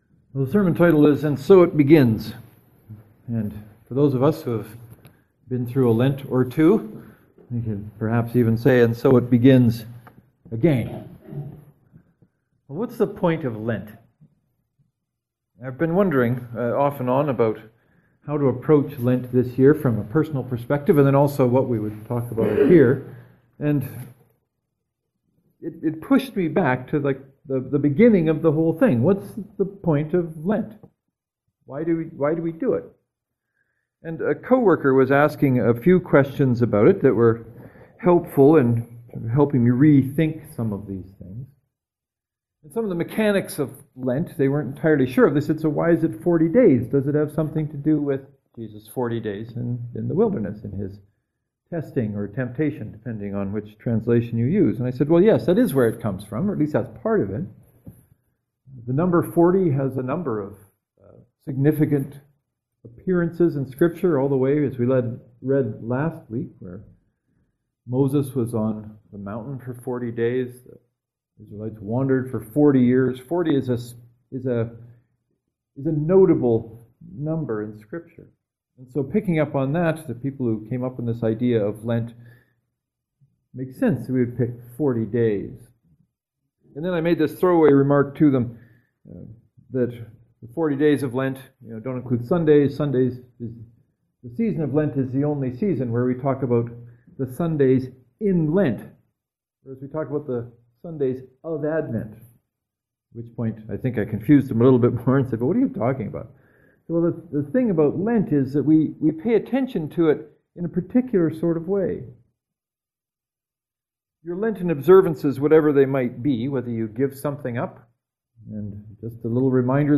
The sermons today are intended to give some direction to why we might choose to observe Lent as well as a suggestion or two about how that observation might play itself out.